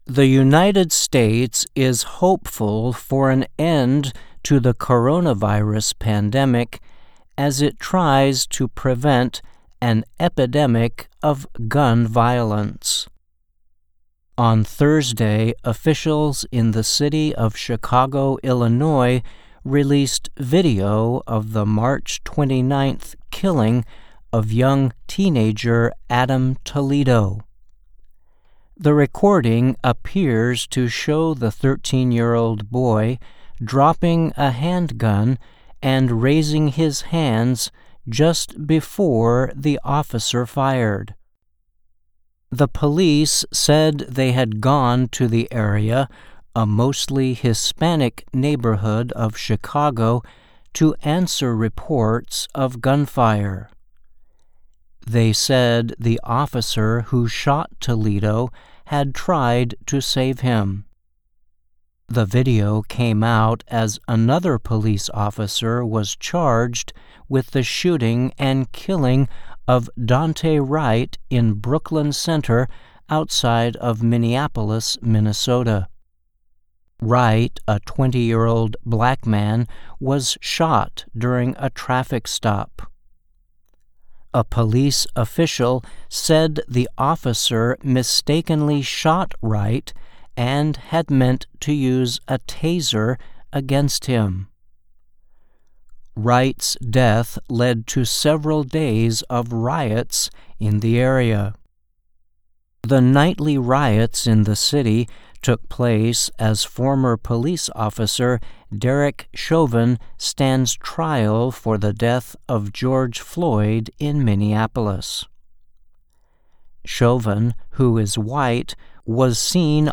慢速英语:美国与不断上升的枪支暴力作斗争